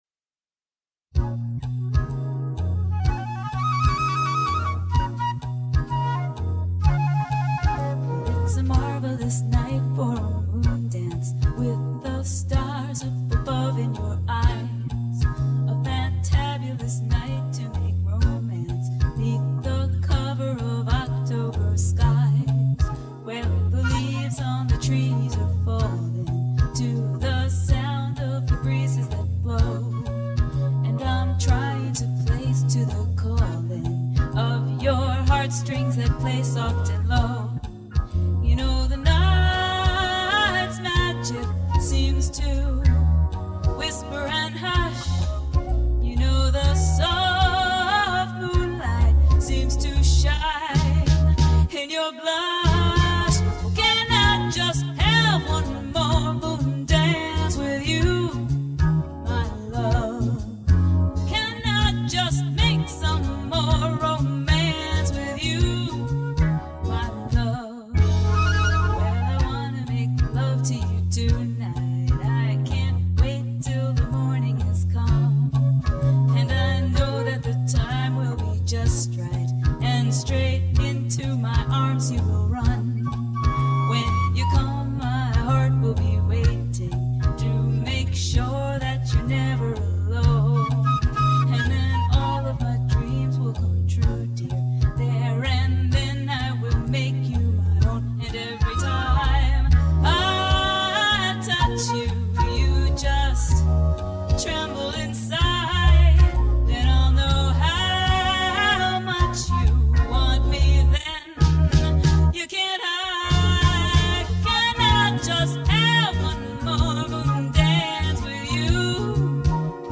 This CD was recorded at TallMan Studios in 1988.
organ
flute
bass
drums